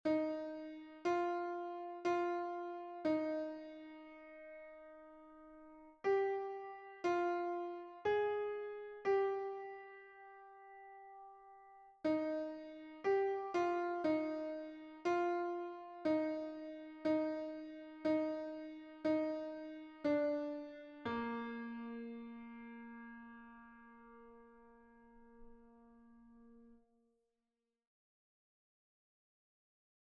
Version piano
Alto Mp 3